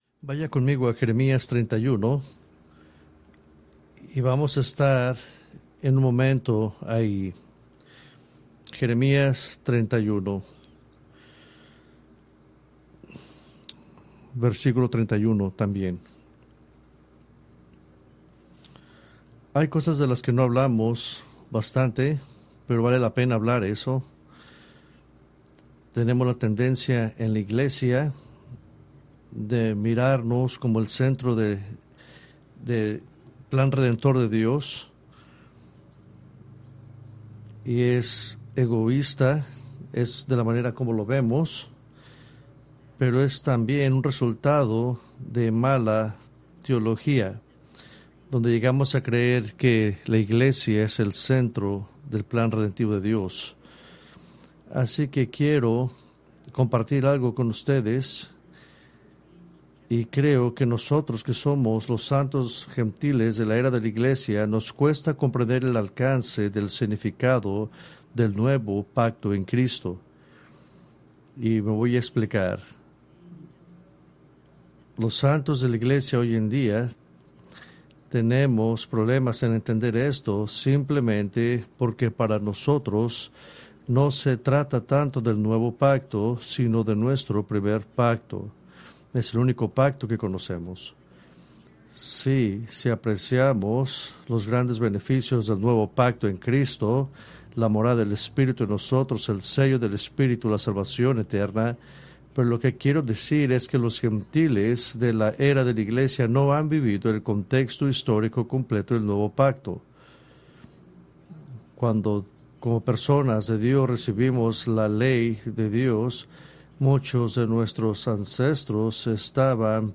Preached May 4, 2025 from Escrituras seleccionadas